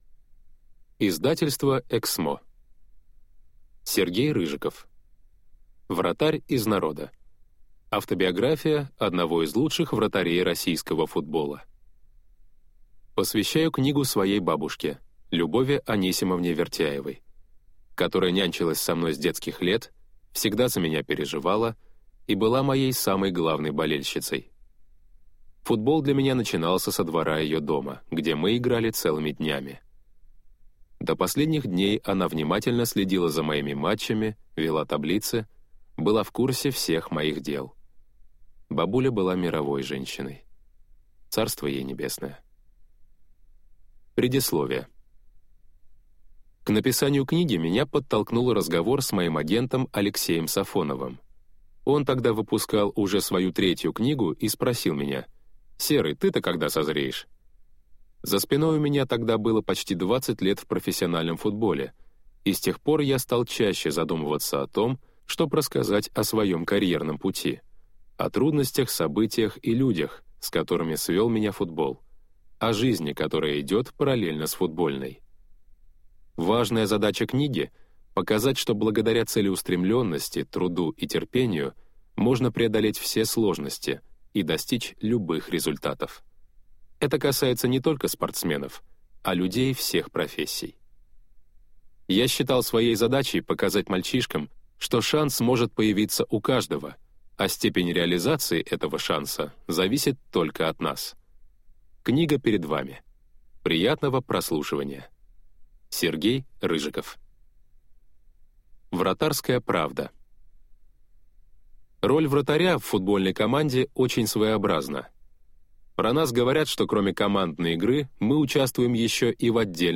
Аудиокнига Вратарь из народа. Автобиография одного из лучших вратарей российского футбола | Библиотека аудиокниг